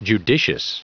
Prononciation du mot judicious en anglais (fichier audio)
Prononciation du mot : judicious